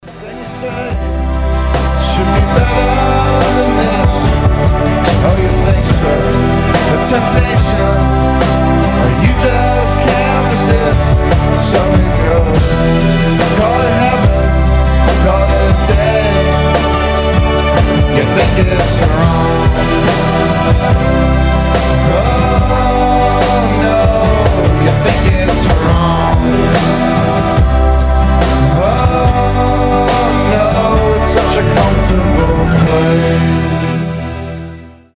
• Качество: 192, Stereo
гитара
спокойные
Спокойная композиция